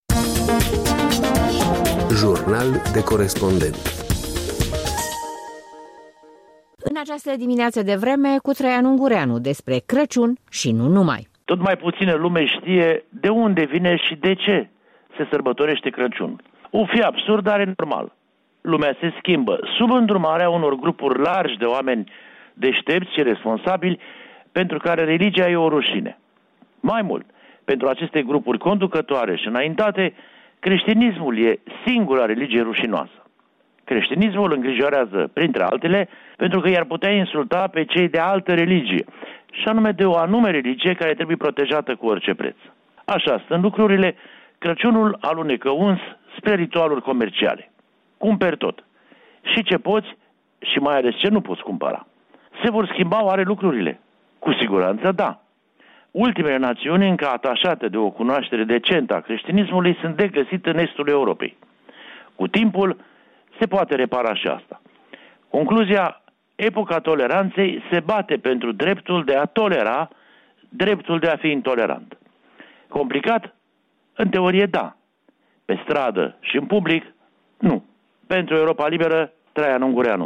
Jurnal de corespondent: Traian Ungureanu (Londra)